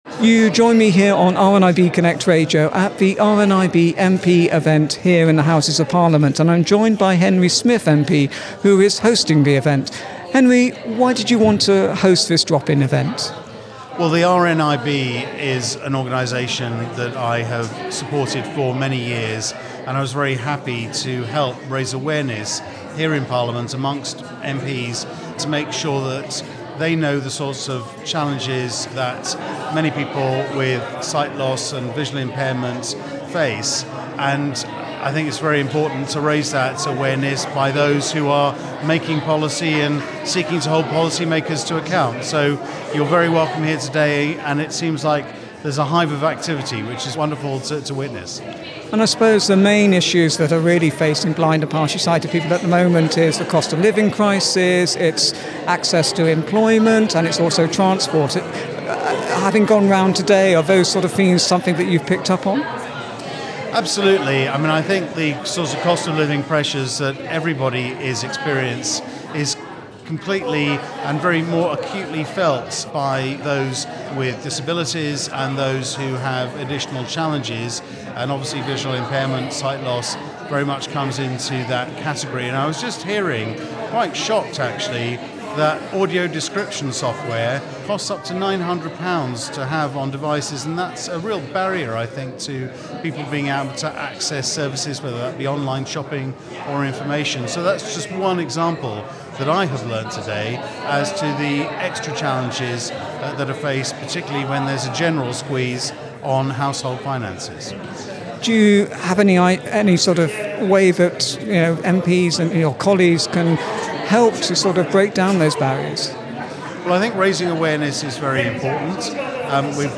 Henry Smith Conservative MP Hosts RNIB Drop-In Event at the Houses of Parliament